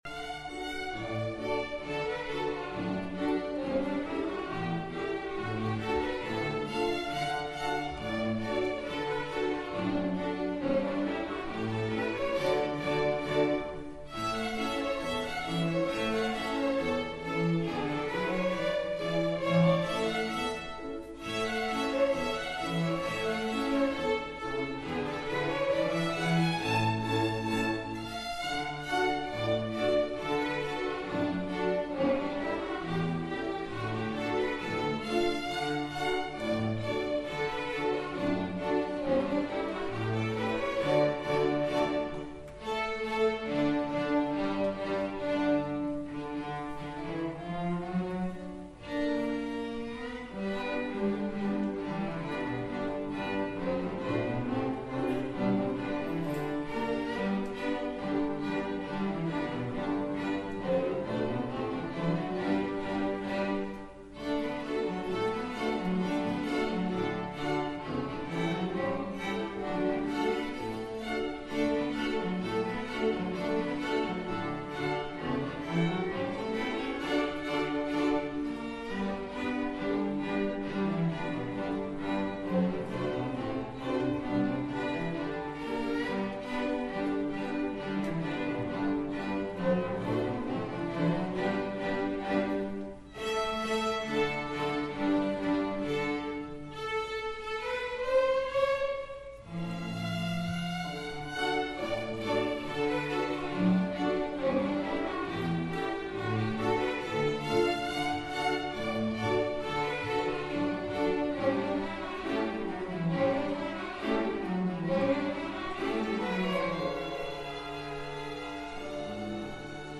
Strings and Orchestra at Saffron